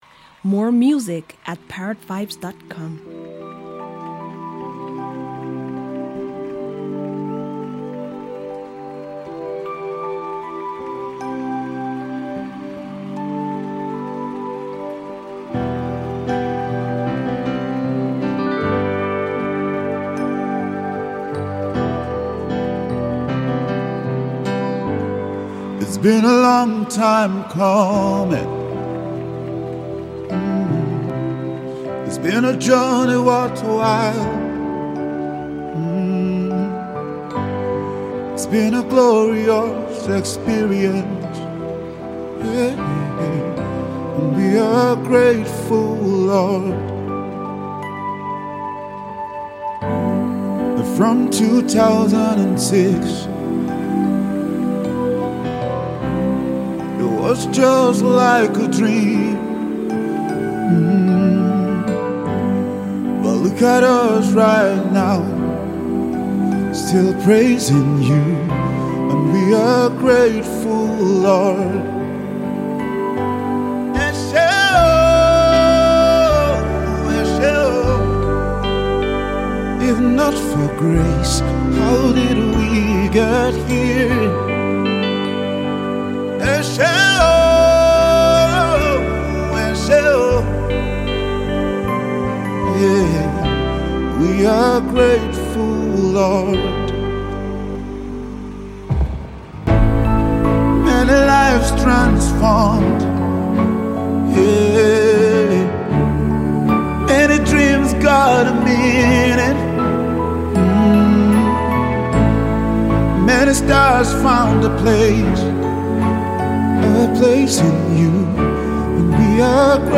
Extraordinarily talented Nigerian gospel vocalist
soul-stirring song